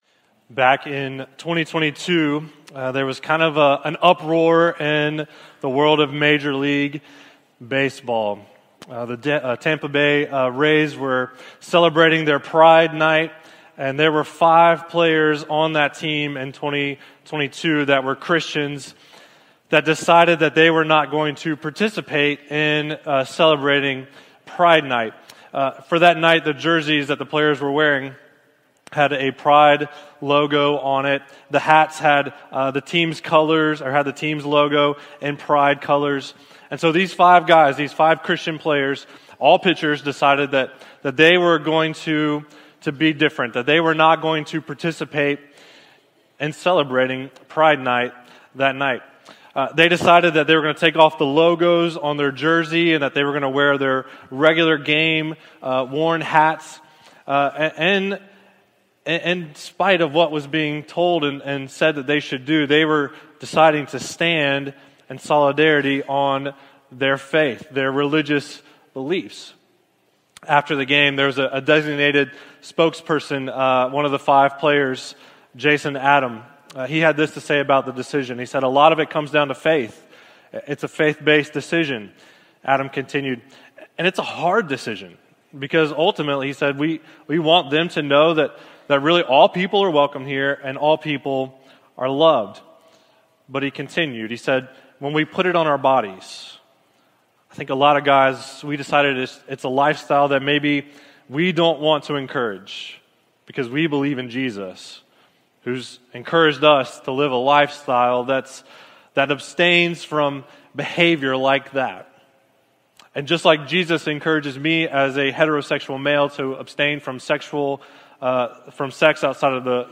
Sermons | Jefferson Christian Church